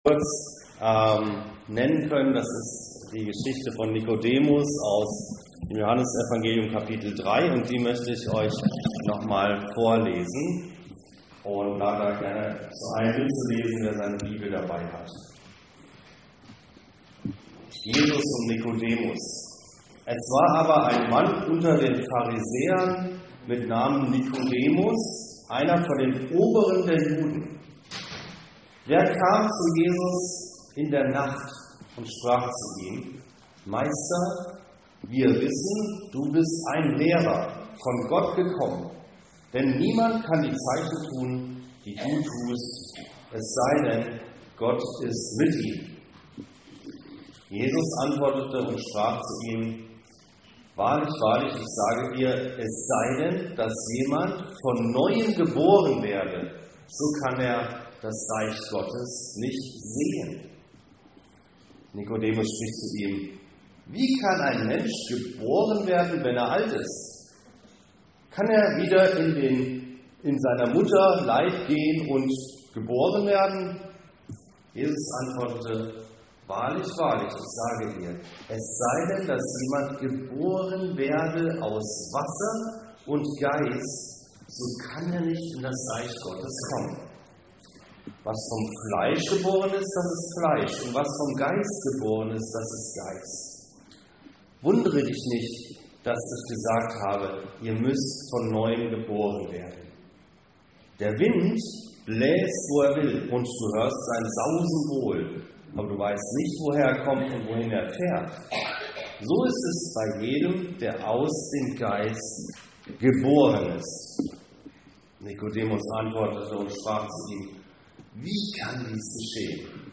Geboren aus Wasser und Geist- im Gespräch mit dem Schriftgelehrten Nikodemus erklärt Jesus, was es heißt, von neuem geboren zu werden. Eine Predigt anlässlich einer Taufe.